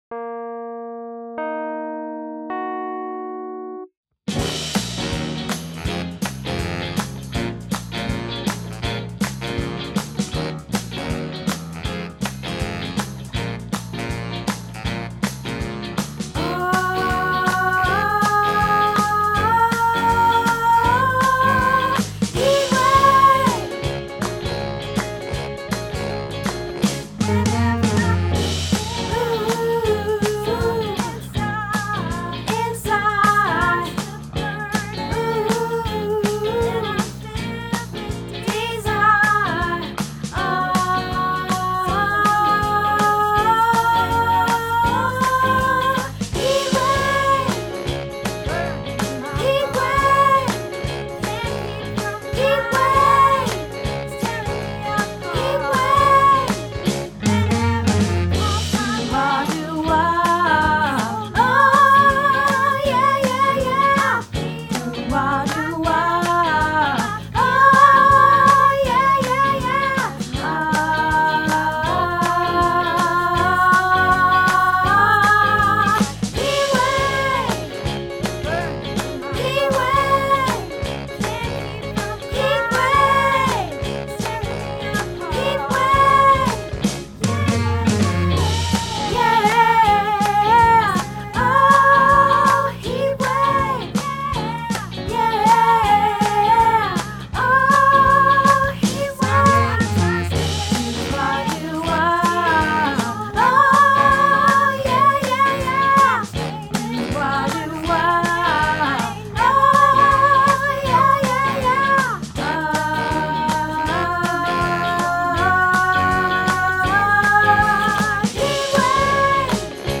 Heatwave - Soprano